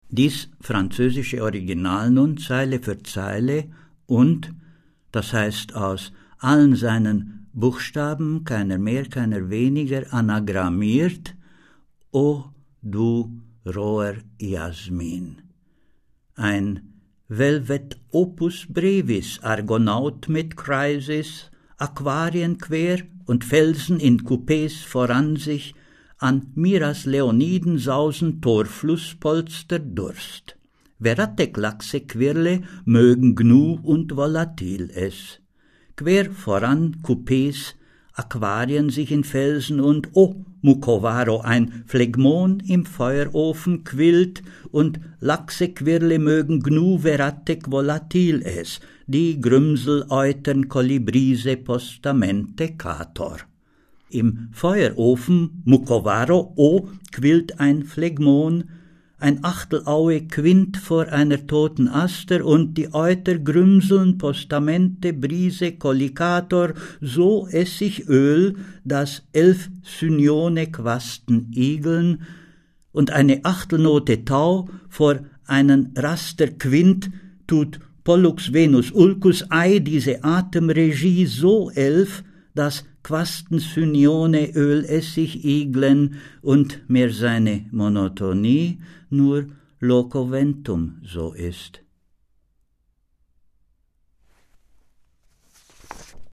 Das Buch wird von einer CD begleitet, auf der Oskar Pastior seine Gedichte liest.